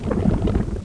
00117_Sound_WtrActn.mp3